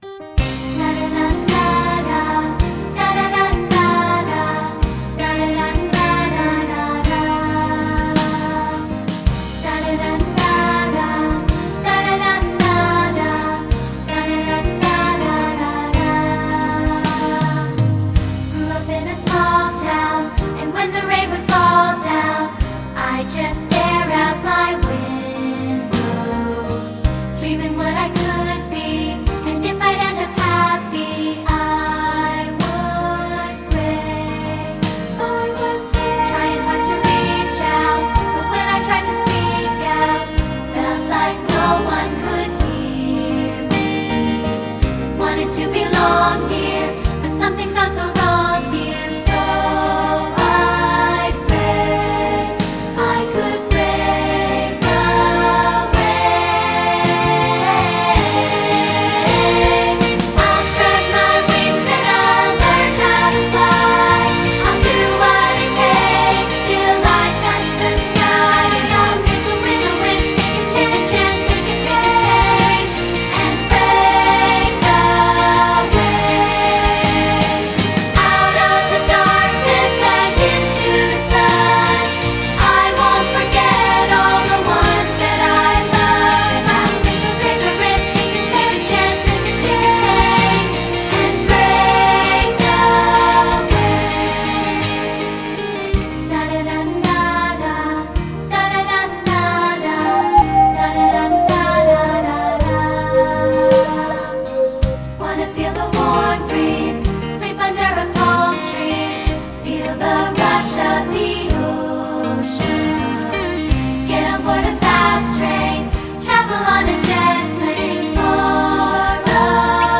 Voicing: 2-Part and Piano